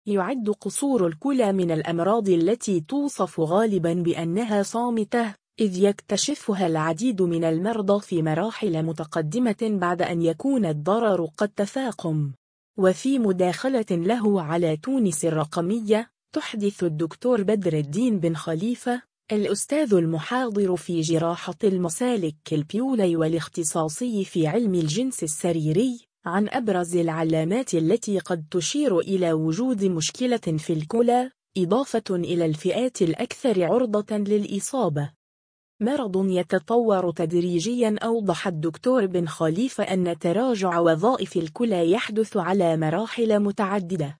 يُعد قصور الكلى من الأمراض التي توصف غالباً بأنها صامتة، إذ يكتشفها العديد من المرضى في مراحل متقدمة بعد أن يكون الضرر قد تفاقم. وفي مداخلة له على تونس الرقمية